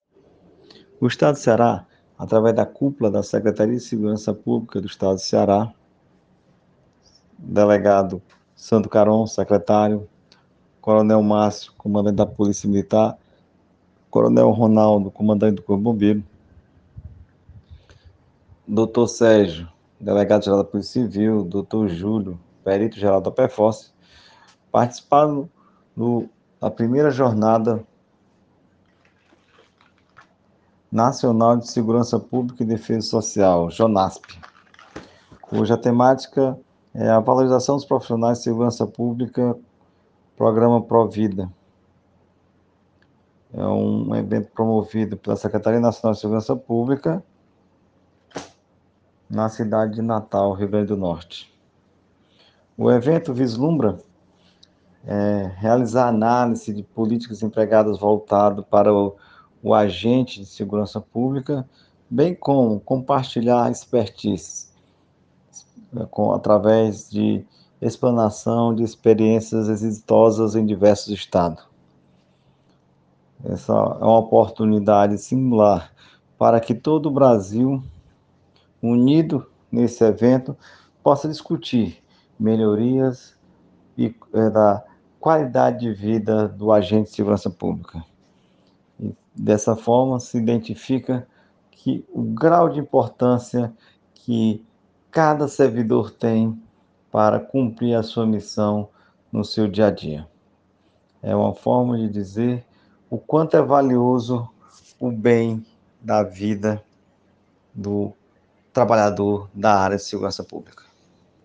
Áudio do Coronel Comandante-Geral, Ronaldo Roque de Araújo.